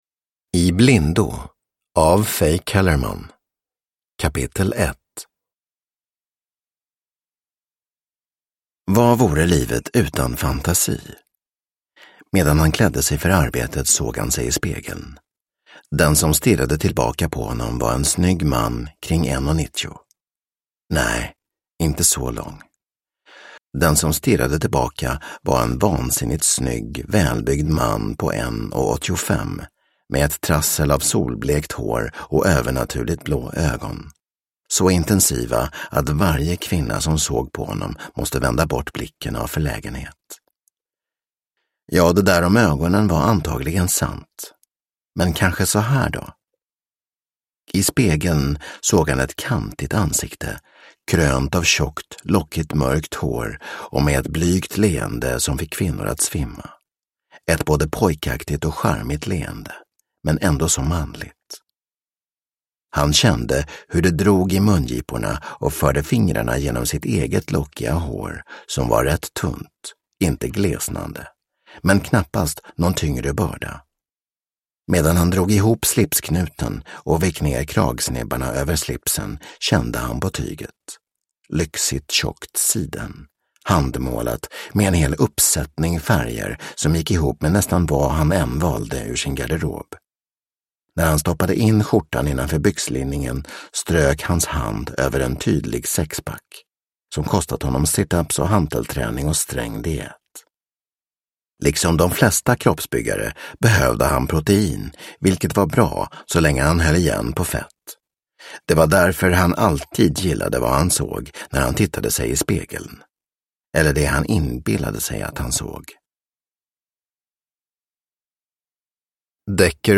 I blindo – Ljudbok – Laddas ner